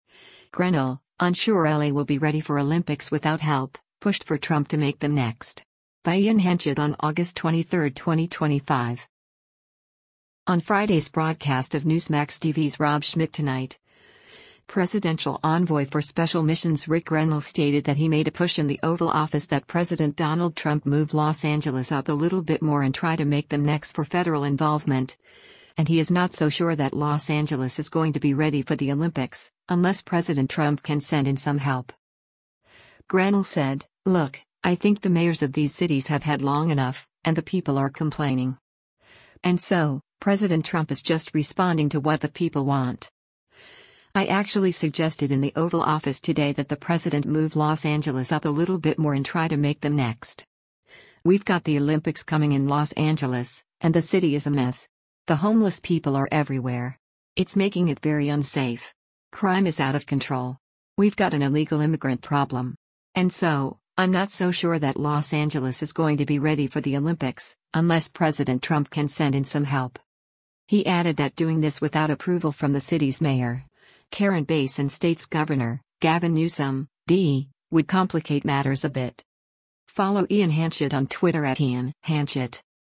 On Friday’s broadcast of Newsmax TV’s “Rob Schmitt Tonight,” Presidential Envoy for Special Missions Ric Grenell stated that he made a push in the Oval Office that President Donald Trump “move Los Angeles up a little bit more and try to make them next” for federal involvement, and he is “not so sure that Los Angeles is going to be ready for the Olympics, unless President Trump can send in some help.”